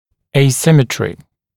[eɪ’sɪmətrɪ][ˌэй’симэтри]асимметрия, нарушение симметрии